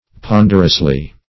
Ponderously \Pon"der*ous*ly\, adv.